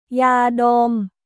ยาดม　ヤードム